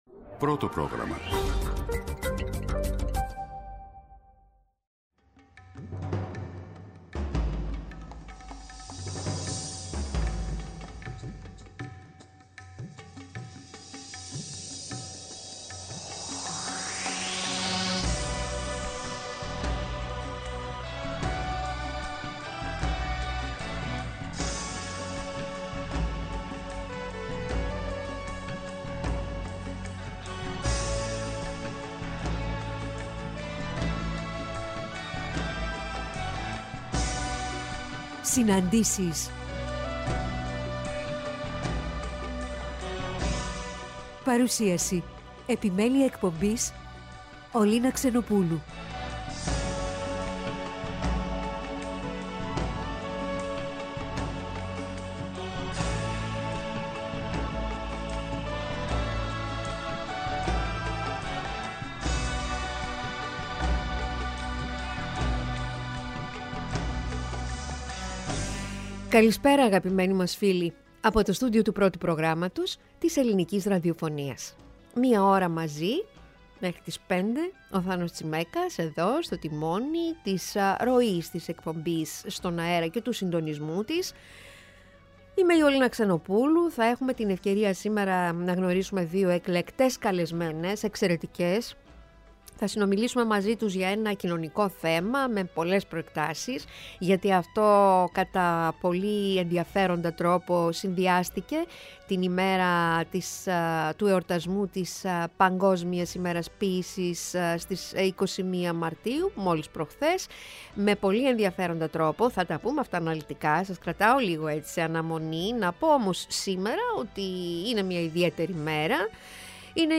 Στις «Συναντήσεις» σήμερα 16:00-17:00 στο Πρώτο Πρόγραμμα:
Μιλά με αφορμή τον Διαγωνισμό Ποίησης που διοργάνωσε ο Δήμος για την Παγκόσμια Ημέρα Ποίησης με την συμμετοχή ανθρώπων της Τρίτης ηλικίας και των λεσχών Φιλίας.